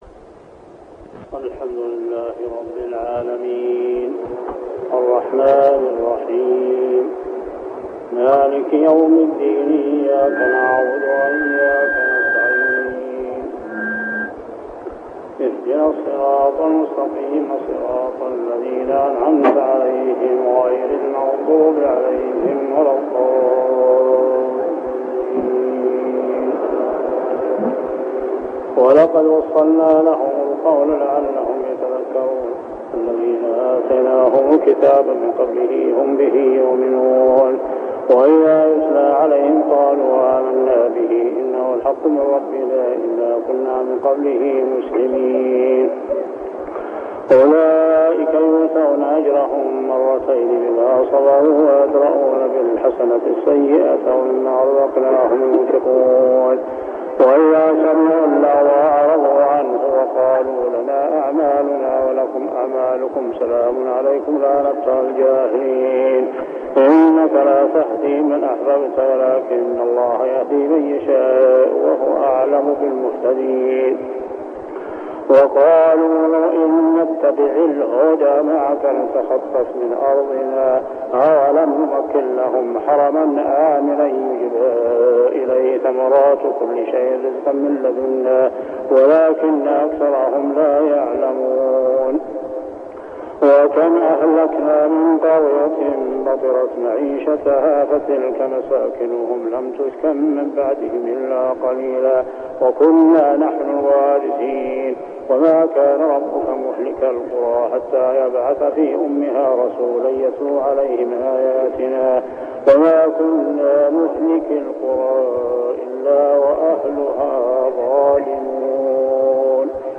صلاة التراويح عام 1399هـ سورتي القصص 51-88 و العنكبوت 1-45 | Tarawih Prayer Surah Al-Qasas and Al-Ankabut > تراويح الحرم المكي عام 1399 🕋 > التراويح - تلاوات الحرمين